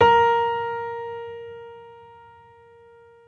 piano-sounds-dev
Vintage_Upright